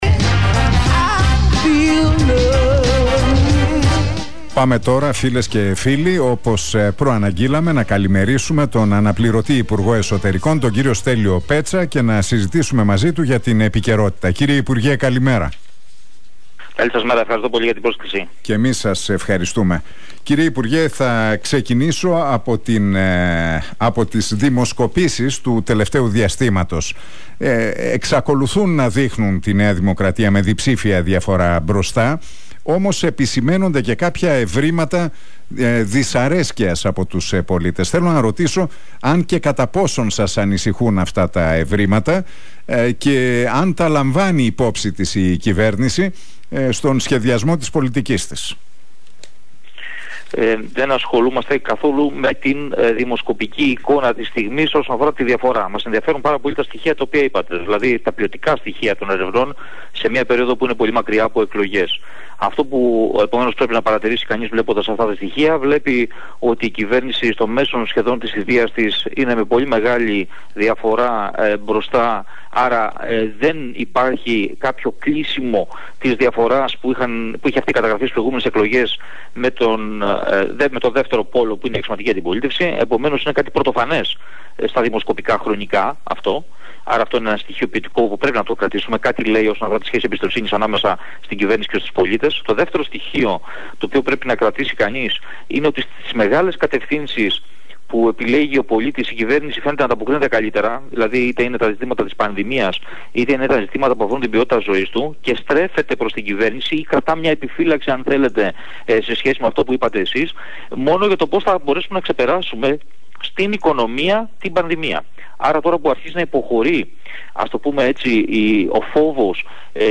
Ο αναπληρωτής υπουργός Εσωτερικών, Στέλιος Πέτσαςμιλώντας στην εκπομπή του Νίκου Χατζηνικολάου στον Realfm 97,8...